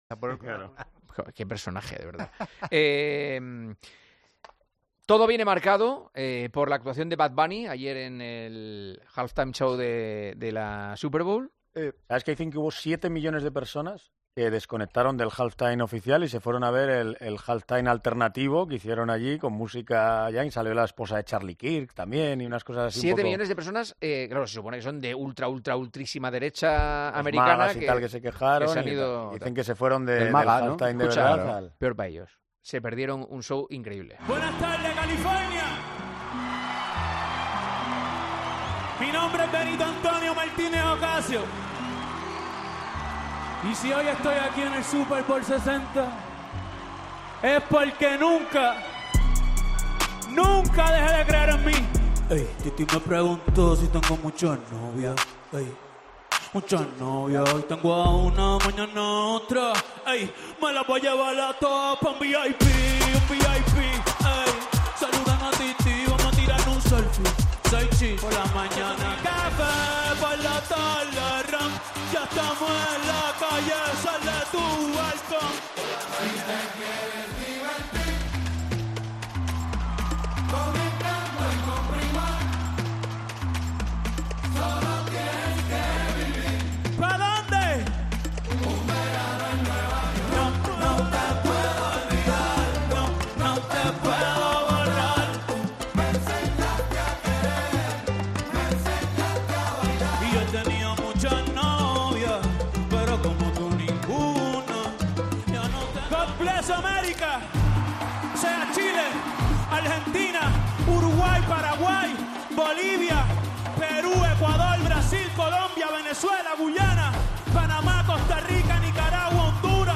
La histórica actuación del artista puertorriqueño en el descanso de la Super Bowl enciende un acalorado debate en El Partidazo de COPE sobre su...